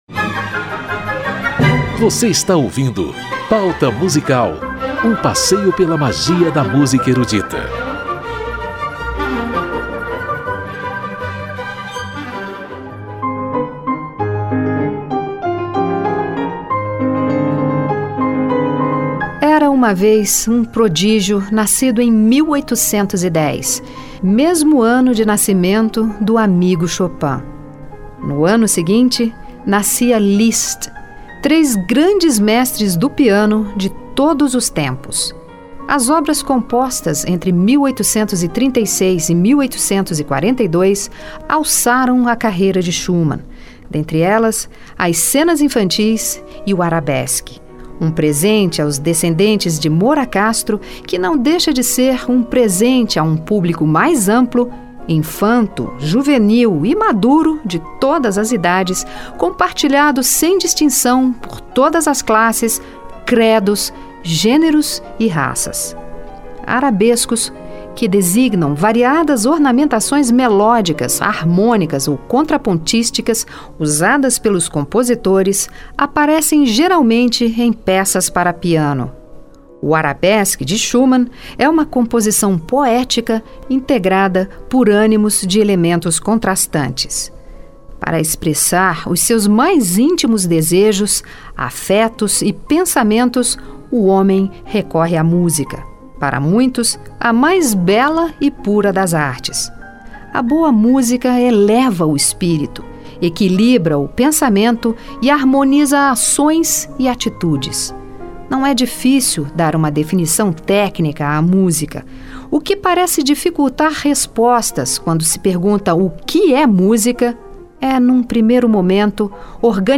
em entrevista exclusiva.